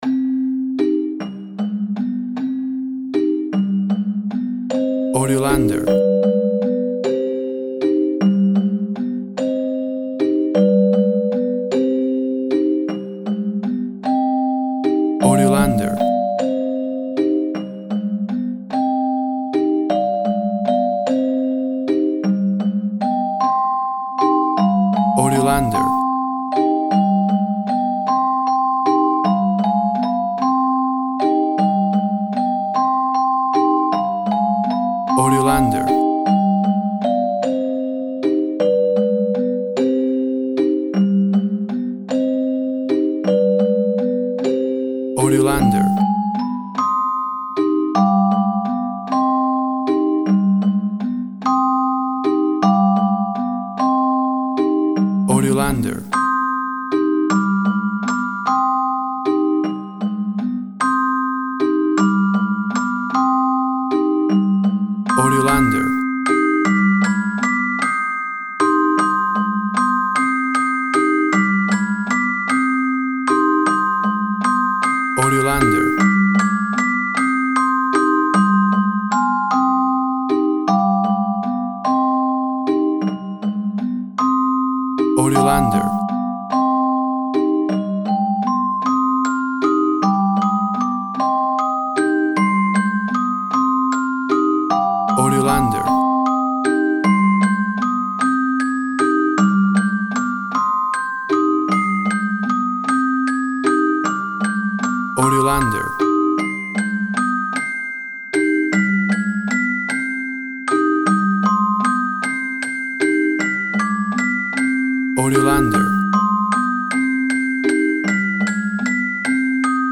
A classic scary nursery rhyme.
Tempo (BPM) 78